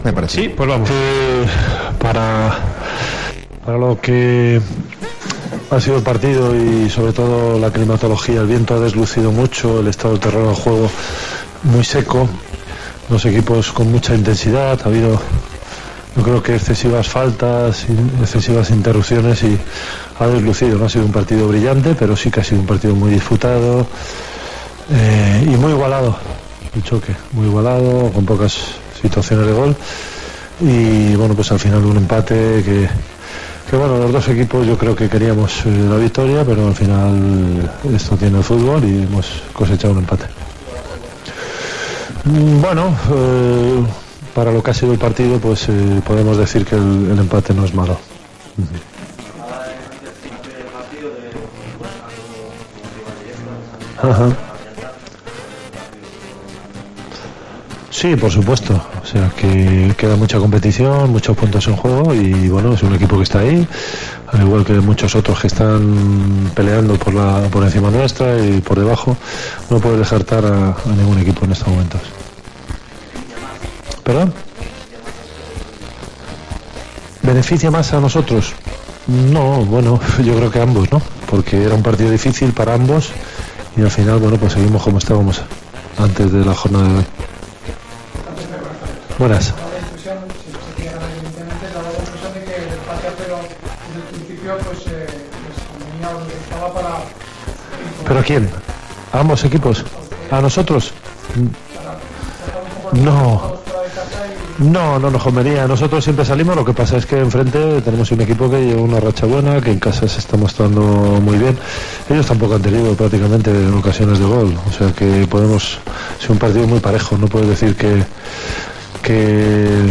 Declaraciones del entrenador del Getafe después de empatar (0-0) contra el Huesca en El Alcoraz.